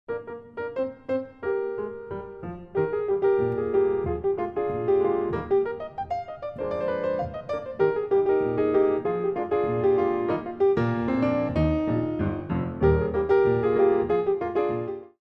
Compositions for Ballet Class
The CD is beautifully recorded on a Steinway piano.